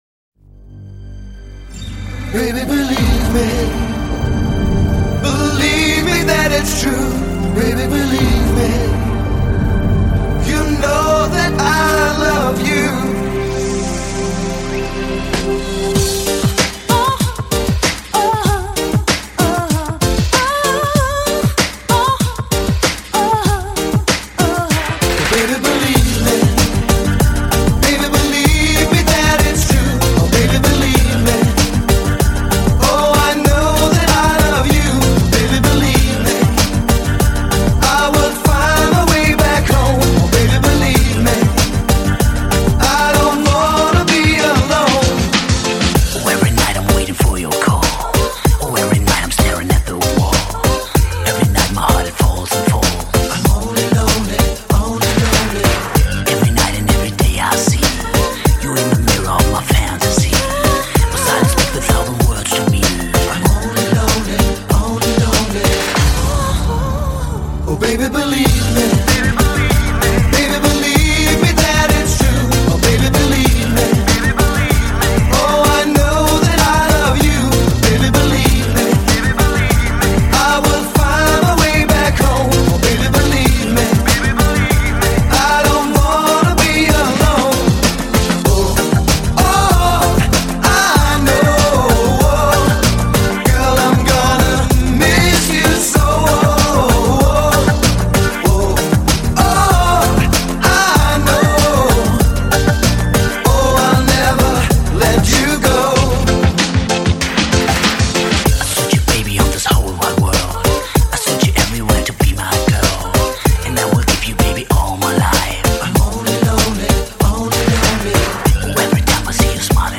Жанр: EuroDisco